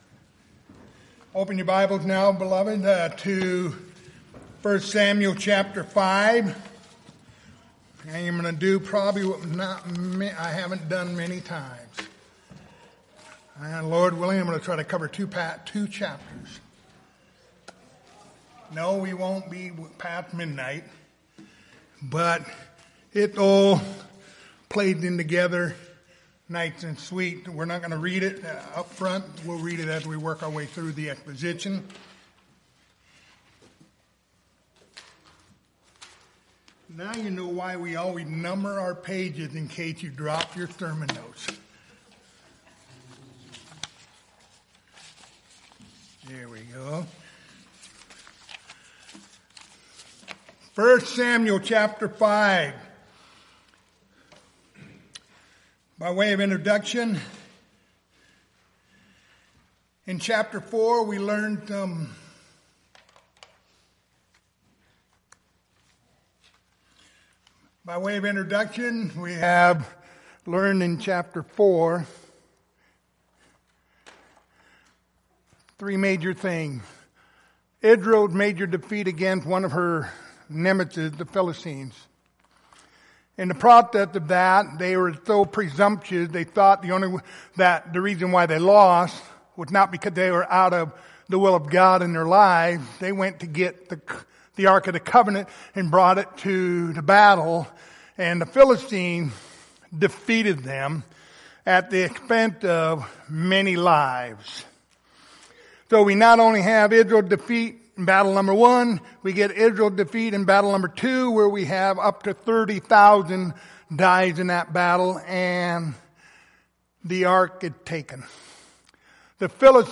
Passage: 1 Samuel 5, 1 Samuel 6 Service Type: Wednesday Evening